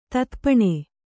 ♪ tathapaṇe